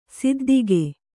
♪ siddige